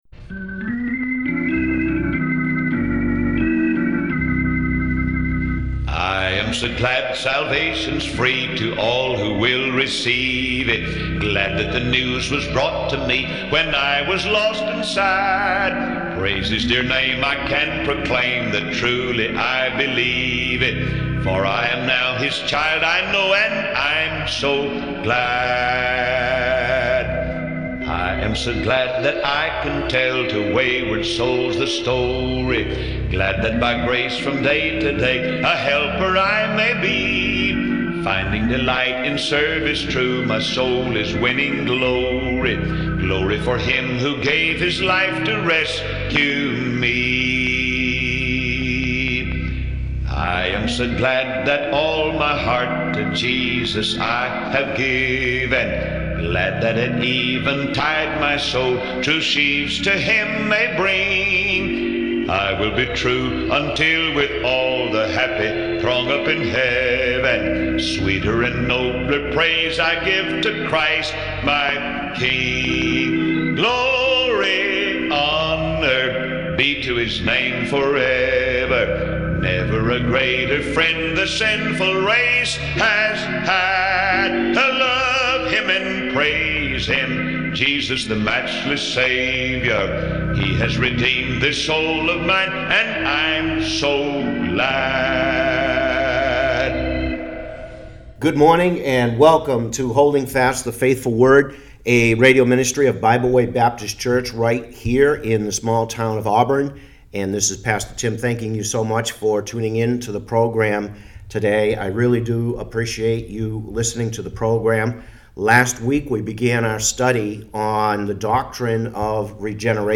Part 2 of the study on the doctrine of regeneration. These are recorded for the radio program so they are not edited for podcast.